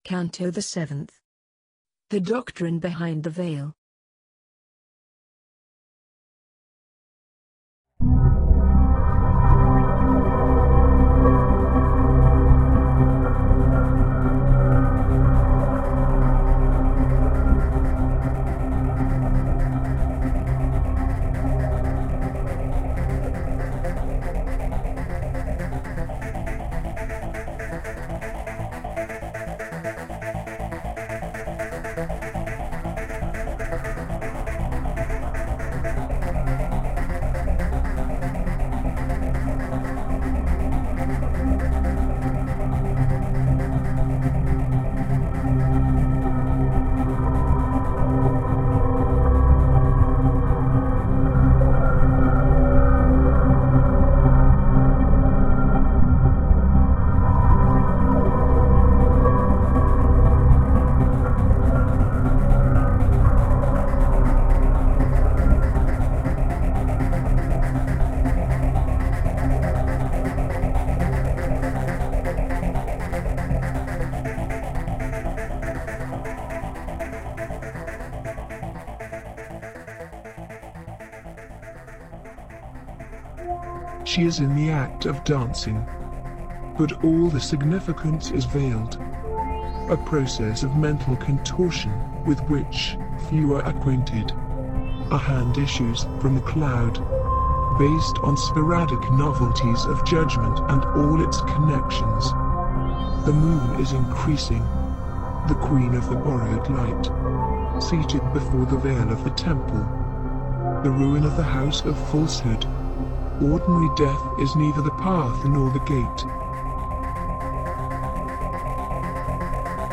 The voices reciting the texts are synthesized.